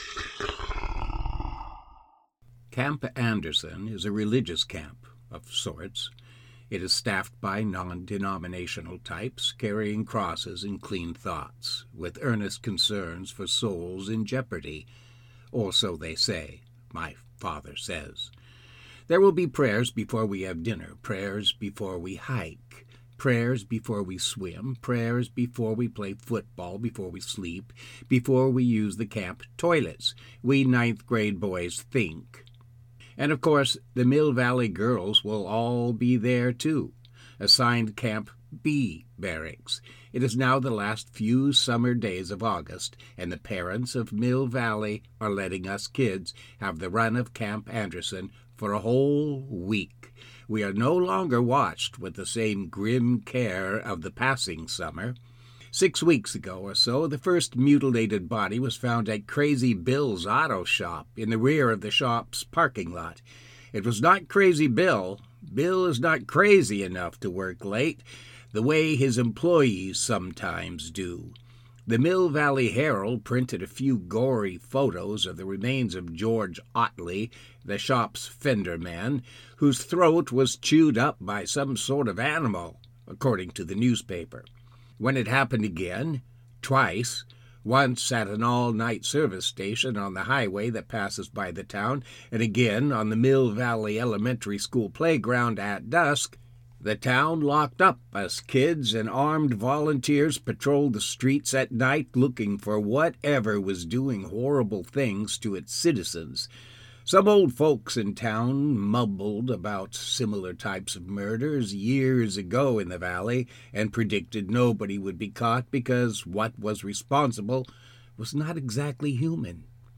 About this Audiobook